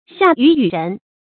成語注音 ㄒㄧㄚˋ ㄧㄩˇ ㄧㄩˇ ㄖㄣˊ
成語拼音 xià yǔ yǔ rén
夏雨雨人發音